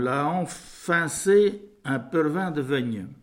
Localisation Sainte-Christine
Catégorie Locution